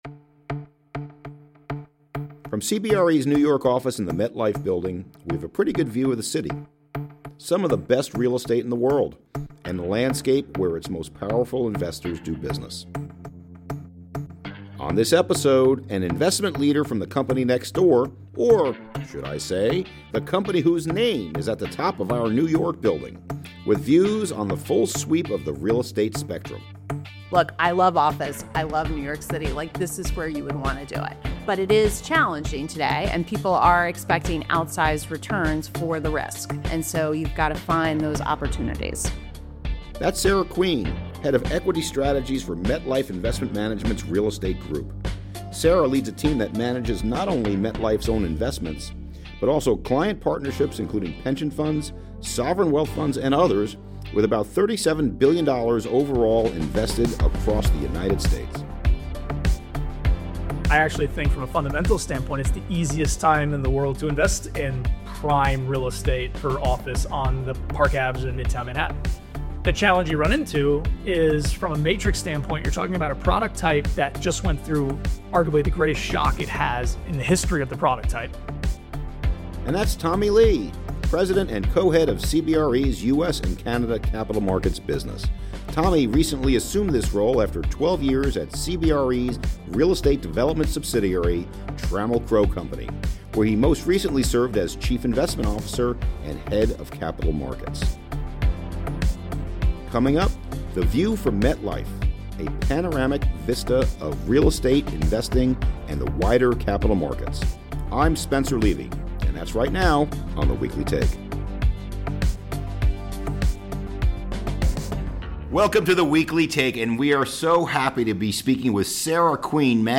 What matters most right now in Commercial Real Estate. Business leaders join economic, industry and subject matter experts to share their distinct views and latest thinking.